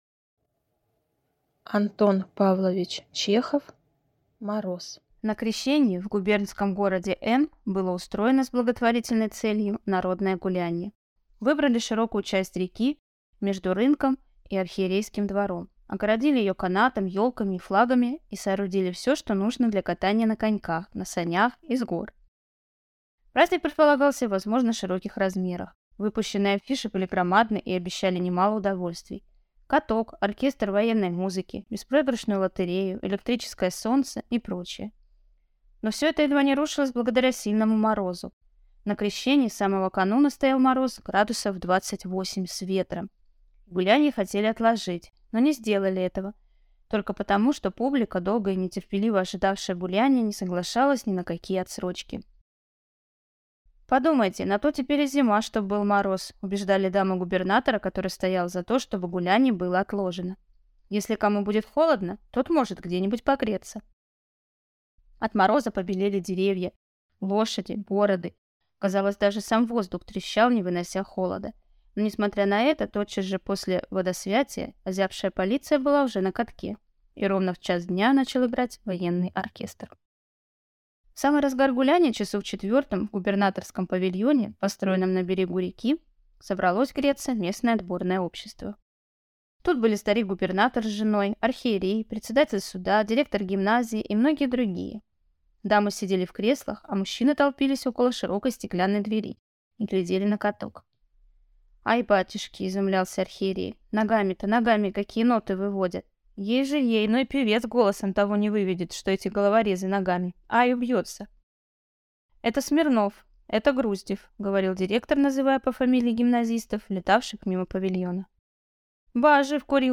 Аудиокнига Мороз | Библиотека аудиокниг